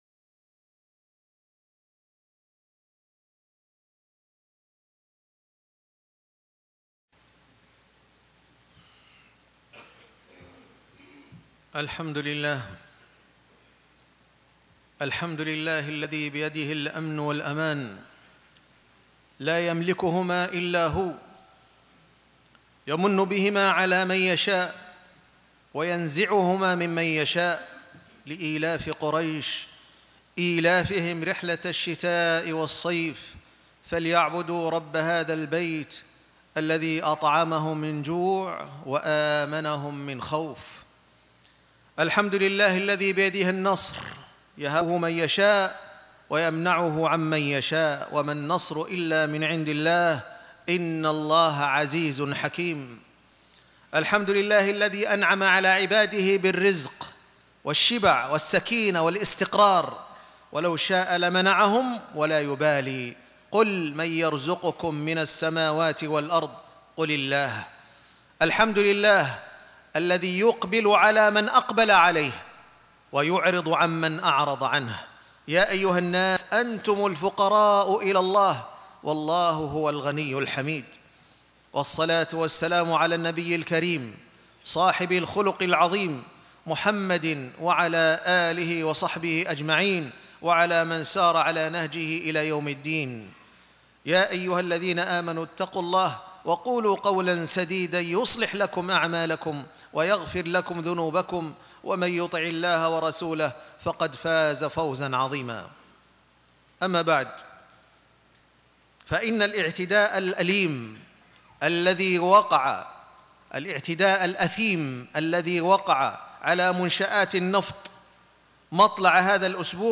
" من حقوق العمال " خطبة الجمعة